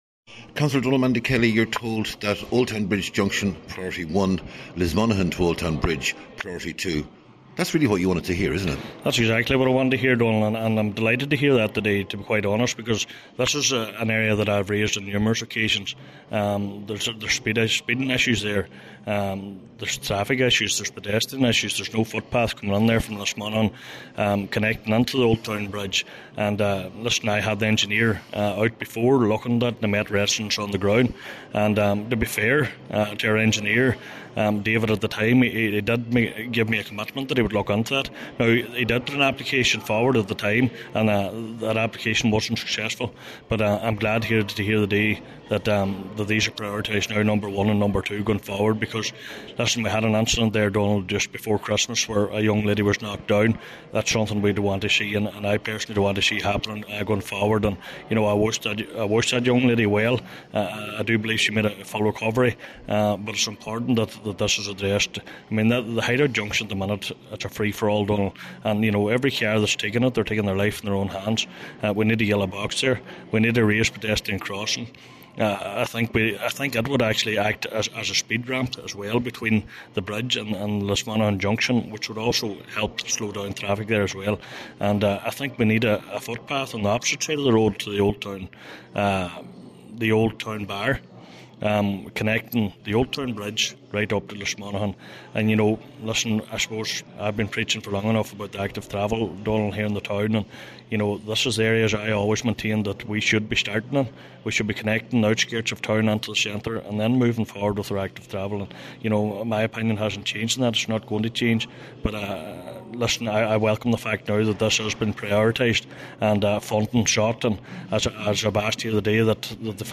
Cllr Kelly says he is glad to hear the news………